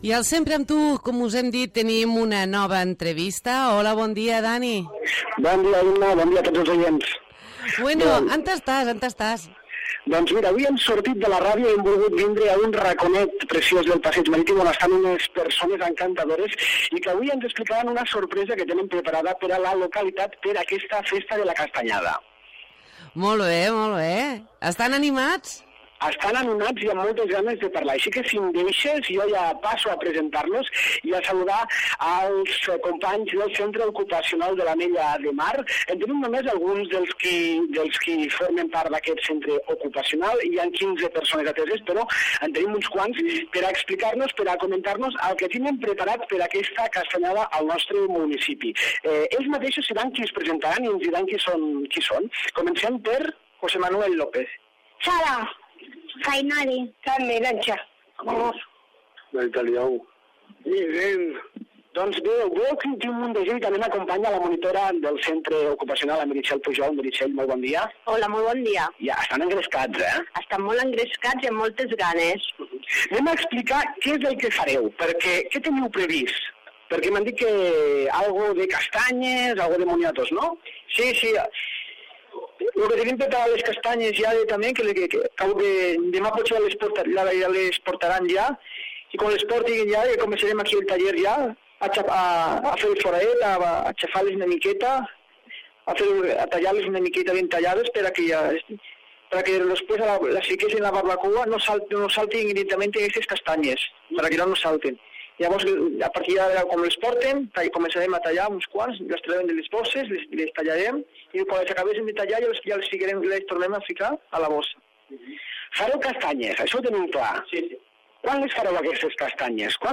El Centre Ocupacional de l'Ametlla de Mar organtiza una parada per vendre castanyes i moniatos per aquest pròxim divendres 30 d'octubre, durant tot el matí, a la Plaça Catalunya. Avui els hem visitat al centre i hem compartit amb ells una entrevista per saber com van els preparatius.